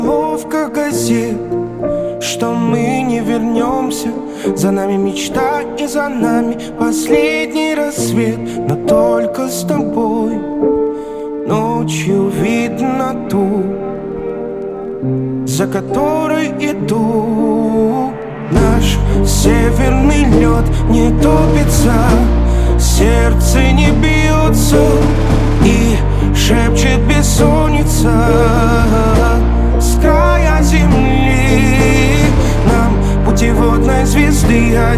Pop Singer Songwriter